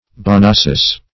Search Result for " bonassus" : The Collaborative International Dictionary of English v.0.48: Bonasus \Bo*na"sus\, Bonassus \Bo*nas"sus\, n. [L. bonasus, Gr.